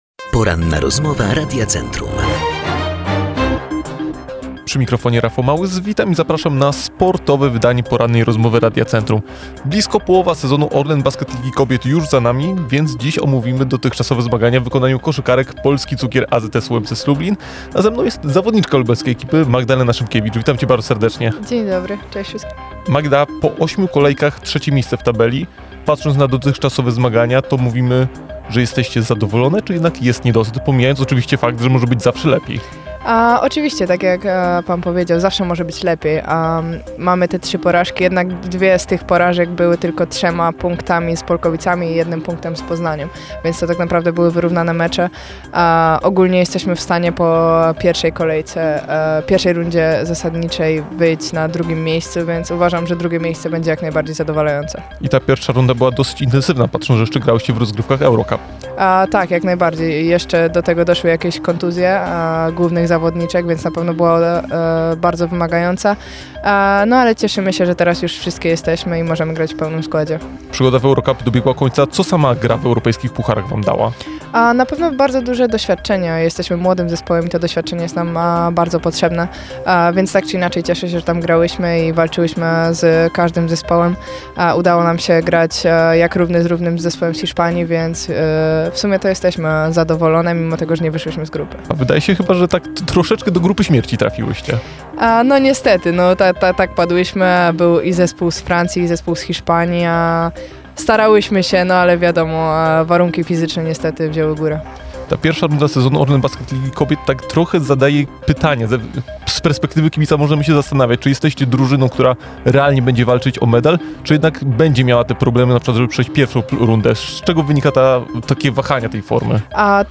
ROZMOWA-10.mp3